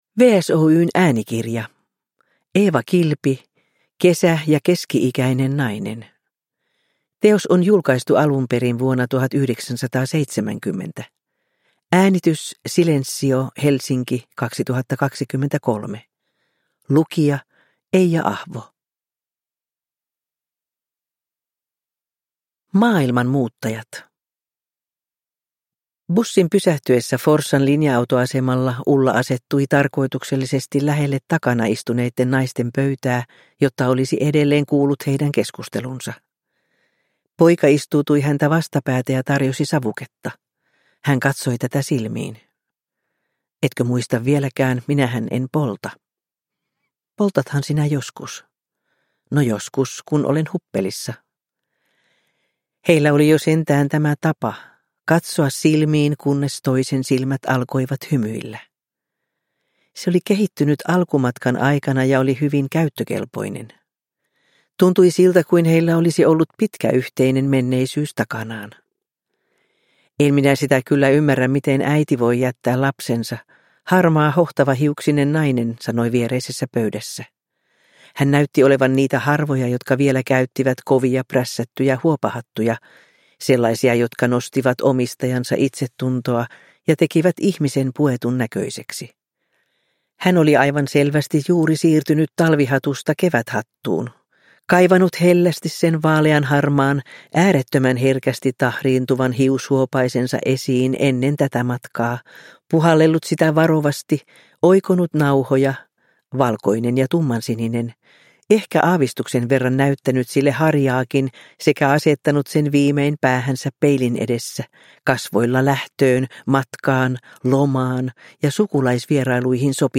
Kesä ja keski-ikäinen nainen – Ljudbok – Laddas ner